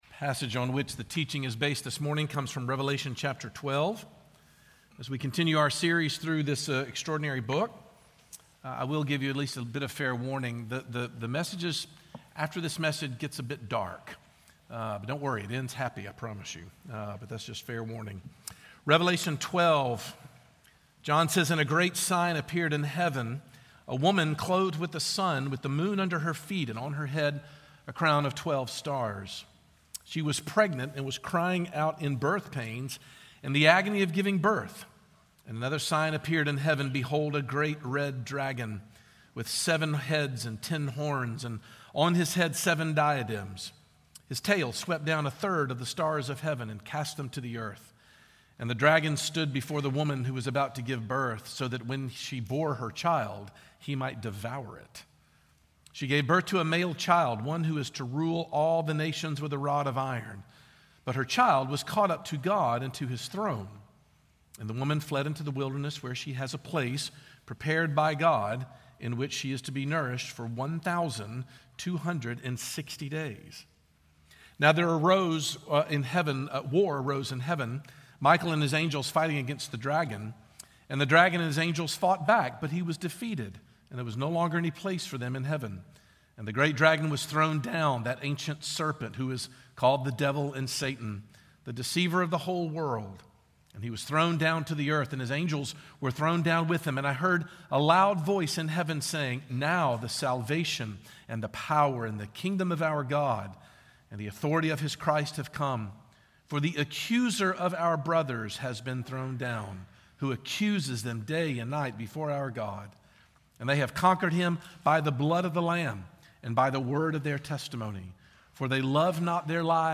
Revelation 12's answer is that it is due to the rage of the Devil, the mighty dragon. But the chapter includes the powerful ways in which God'd people ultimately prevail. Sermon Points: